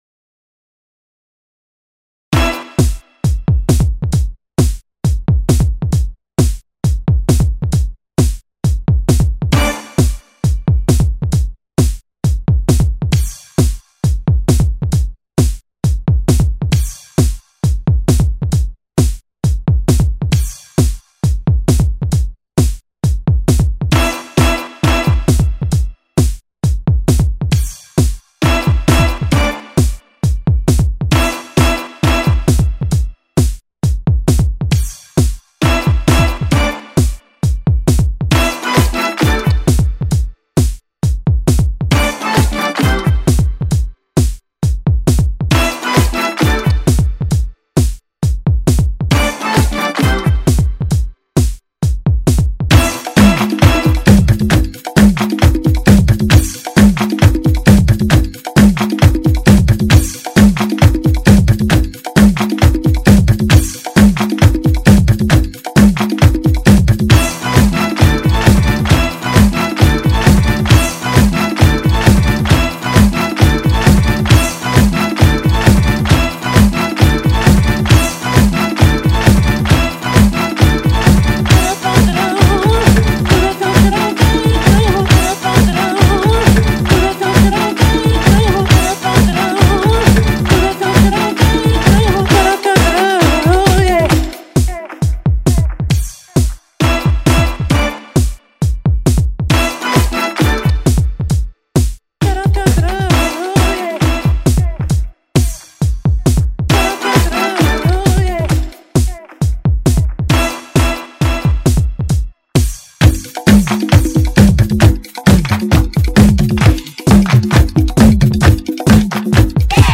horns and percussion bring the dance floor to it's knees.